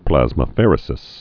(plăzmə-fĕrĭ-sĭs, -fə-rē-)